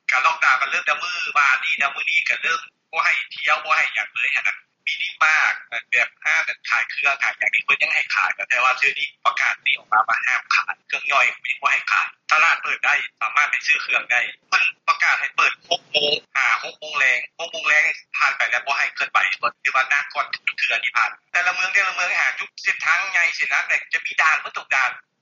ສຽງຂອງປະຊາຊົນຄົນນຶ່ງໃນນະຄອນວຽງຈັນ:
ຄຳເຫັນຂອງປະຊາຊົນຄົນນຶ່ງໃນນະຄອນວຽງຈັນ